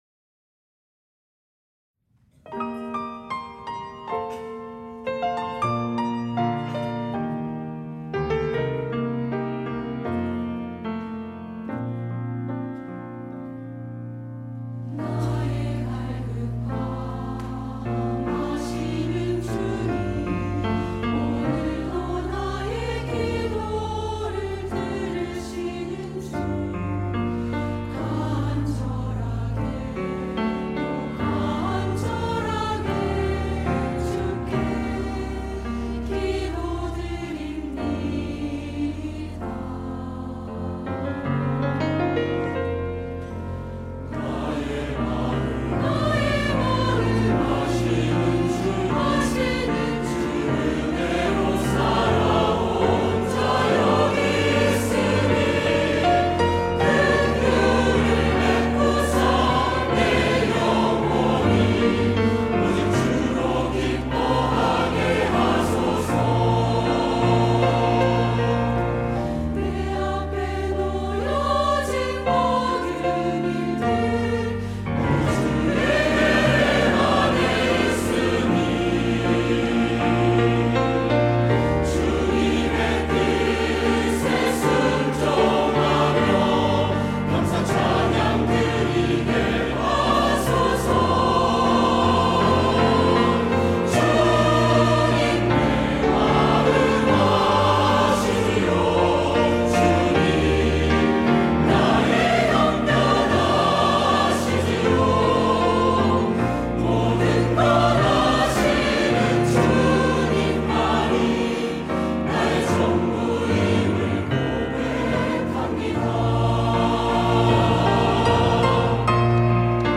할렐루야(주일2부) - 주님만이 나의 전부입니다
찬양대